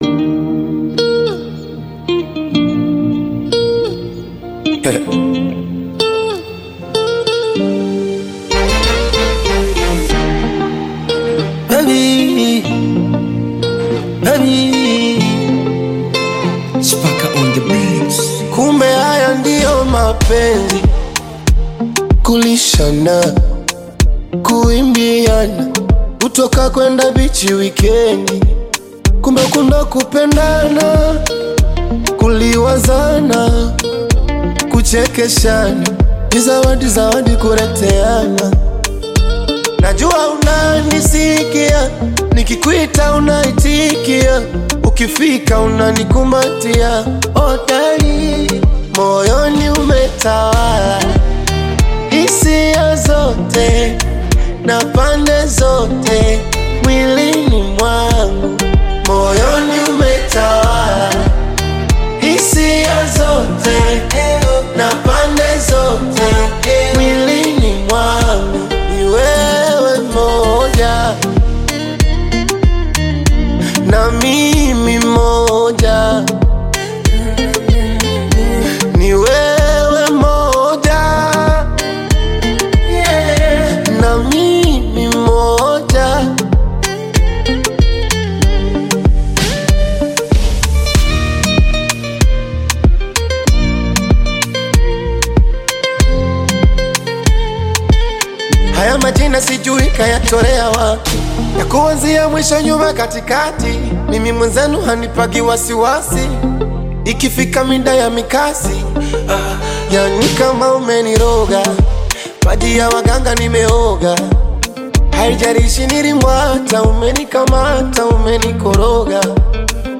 Tanzanian Bongo Flava artist, singer and photographer
Bongo Flava song